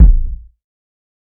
TC3Kick5.wav